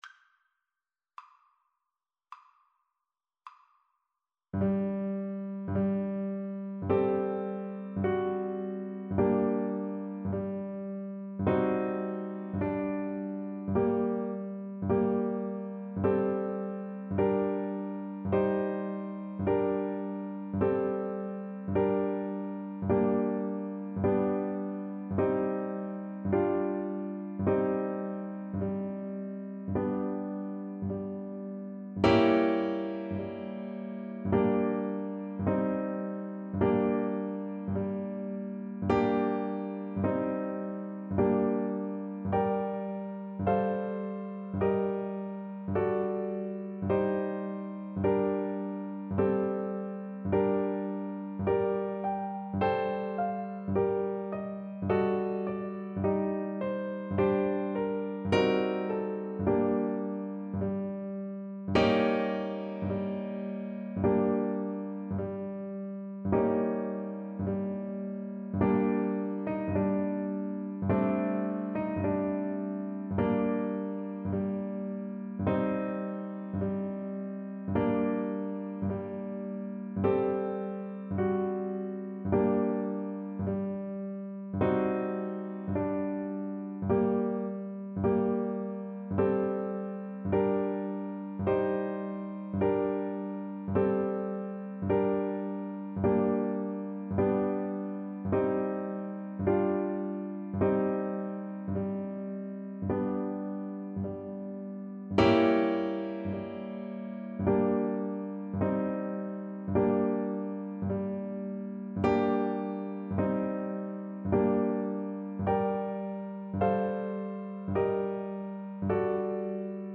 Andante sostenuto =70
Classical (View more Classical Clarinet Music)